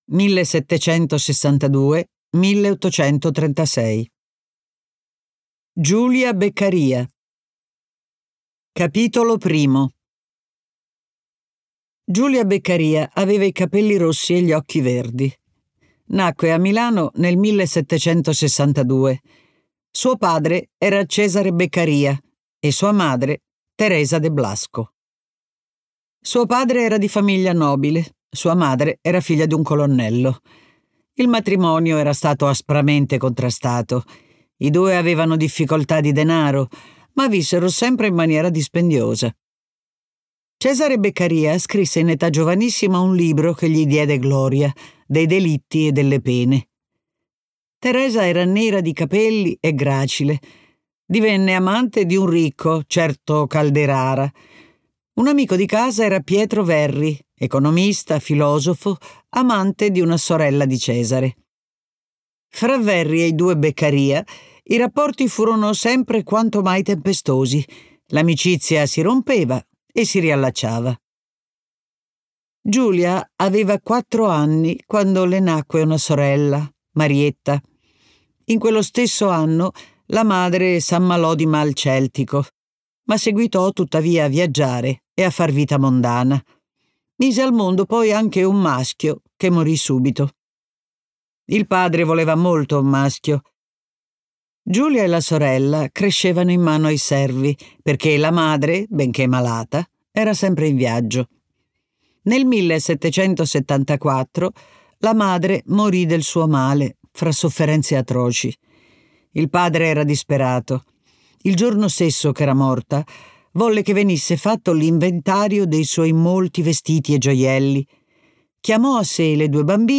letto da Anna Bonaiuto
Versione audiolibro integrale